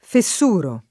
fessurare v.; fessuro [ fe SS2 ro ]